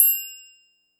rent money triangle.wav